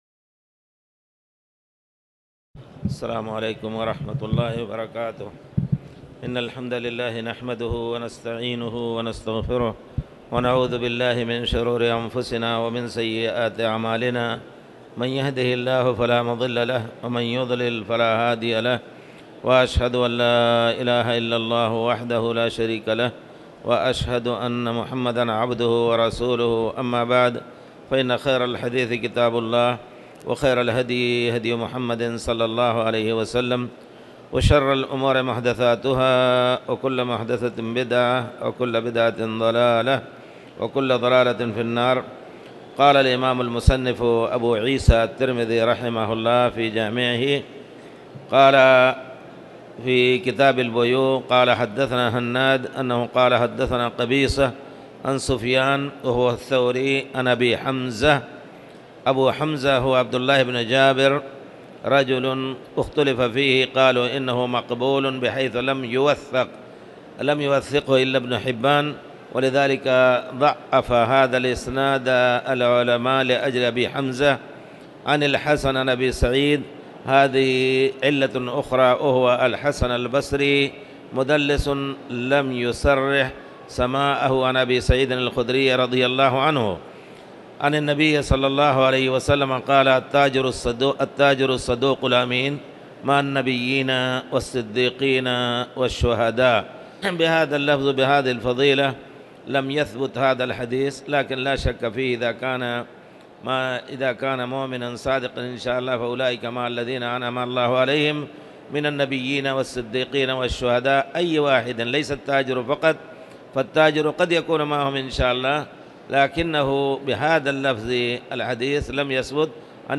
تاريخ النشر ٢٤ صفر ١٤٣٨ هـ المكان: المسجد الحرام الشيخ